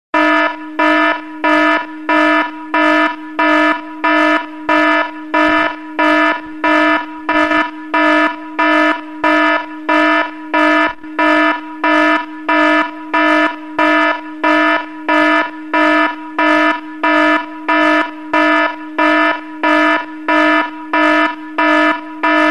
Emergency Alert-sound-HIingtone
emergency-alert_25059.mp3